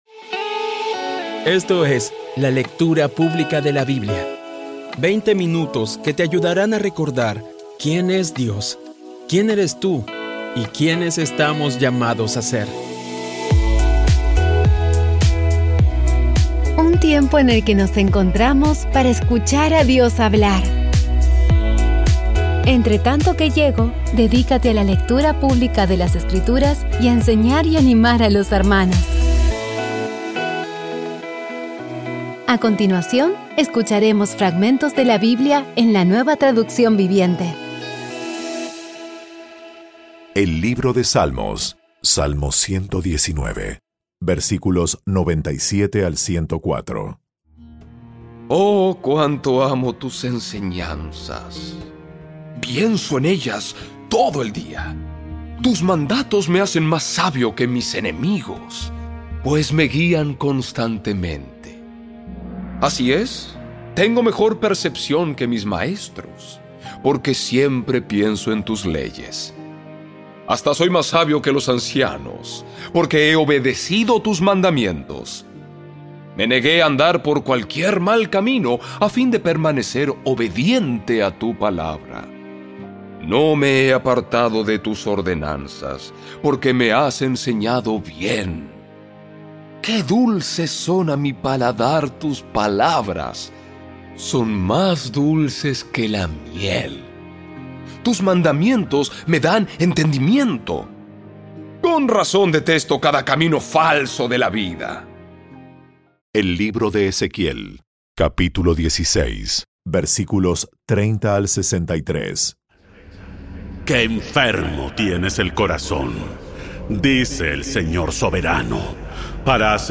Audio Biblia Dramatizada Episodio 308
Poco a poco y con las maravillosas voces actuadas de los protagonistas vas degustando las palabras de esa guía que Dios nos dio.